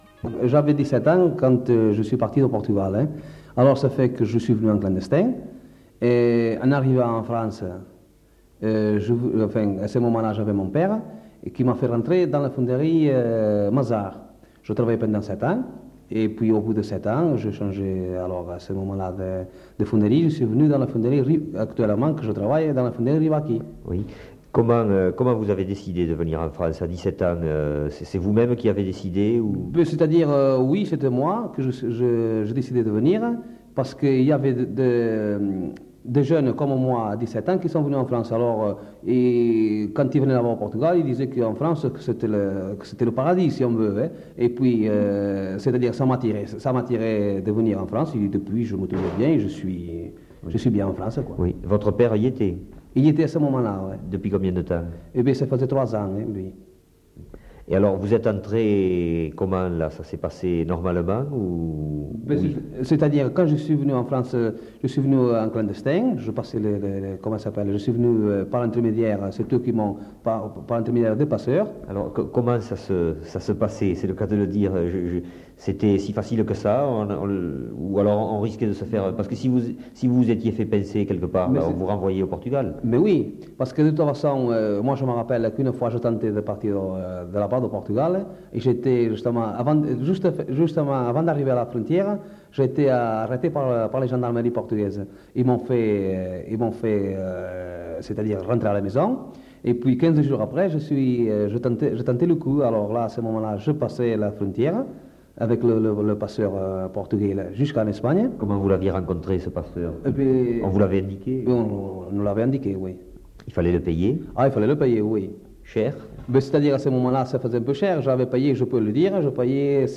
Lieu : Villeréal
Genre : récit de vie